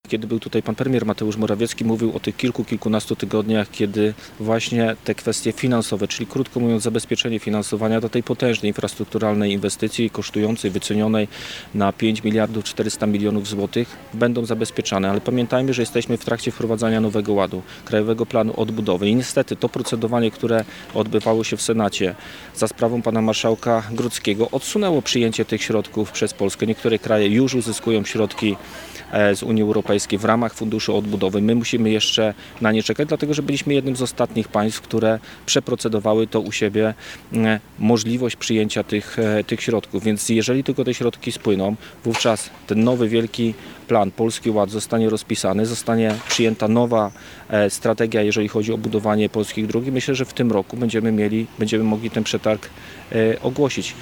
Wojewoda Zachodniopomorski – Zbigniew Bogucki, w specjalnym wywiadzie dla naszego radia powiedział, że ogłoszenie przetargu jest odsunięte w czasie za sprawą Marszałka Grodzkiego.